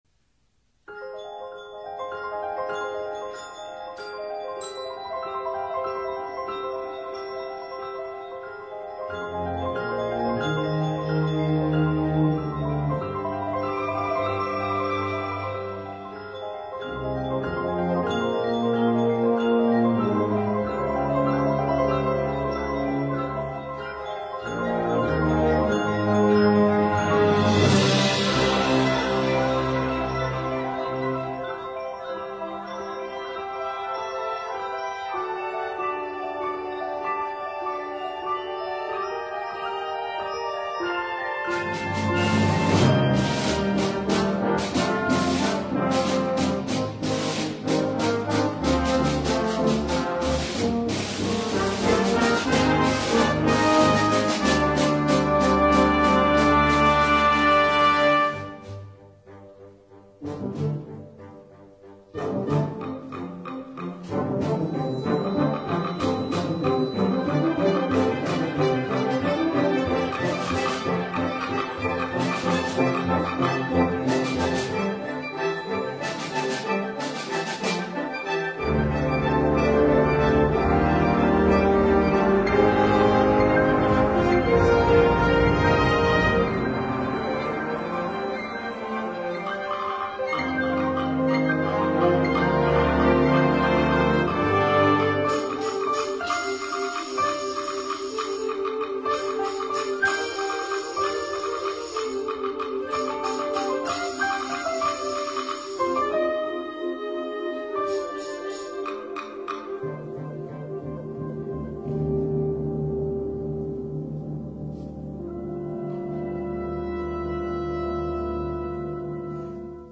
Voicing: Piano and Concert Band